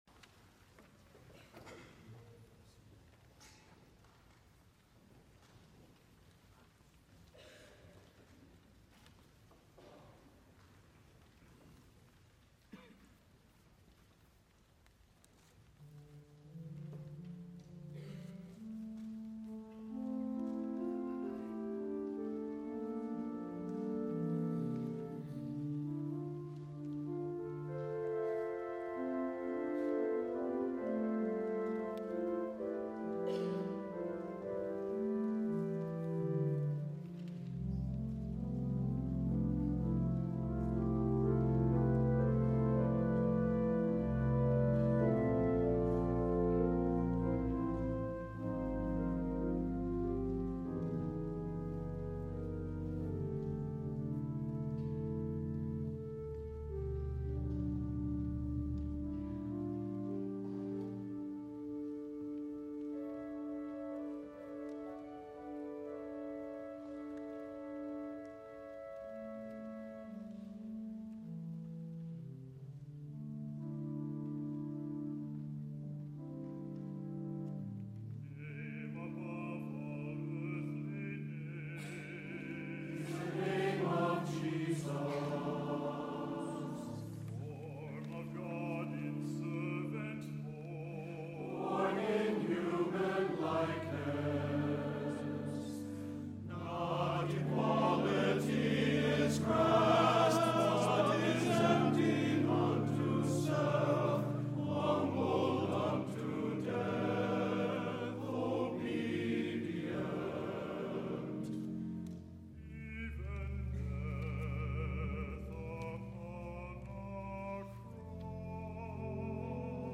An anthem for choir and organ on the dual nature of Christ.
SATB, Baritone solo, organ